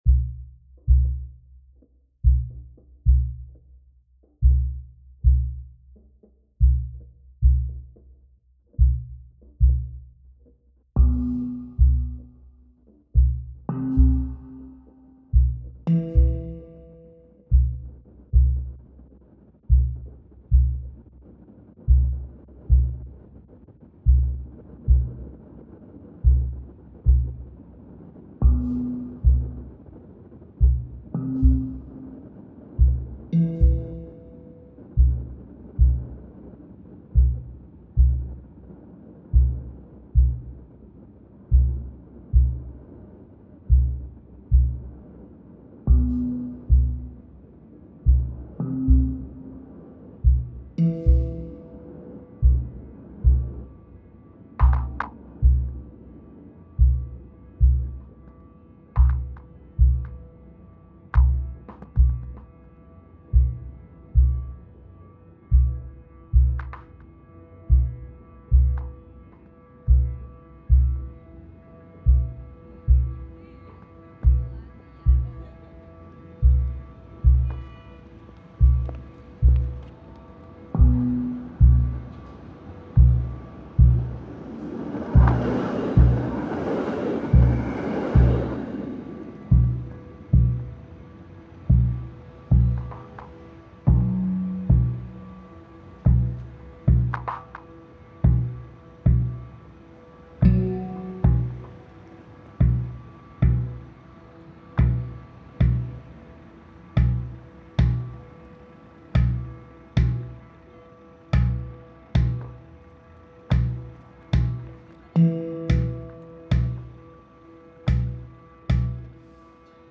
Composition du fond sonore et musical pour l’exposition des photographies
Musique live pour la performance